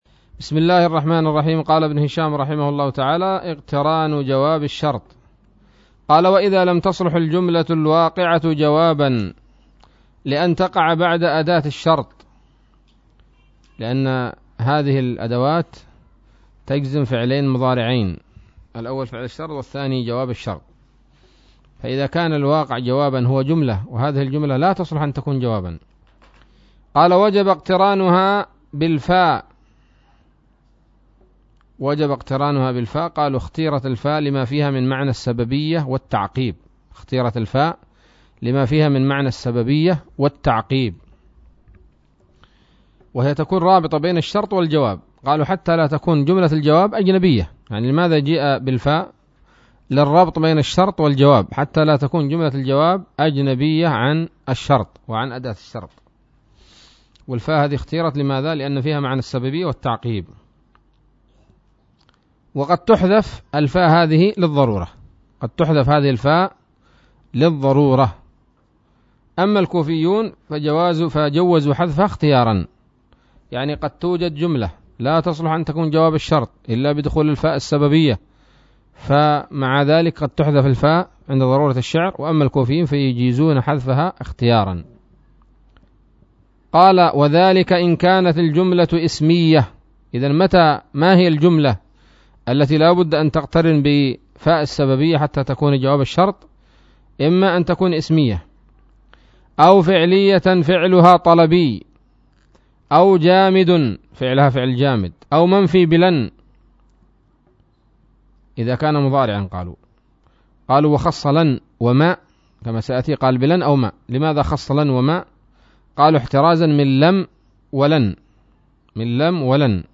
الدرس التاسع والثلاثون من شرح قطر الندى وبل الصدى